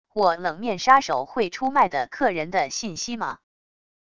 我冷面杀手会出卖的客人的信息吗wav音频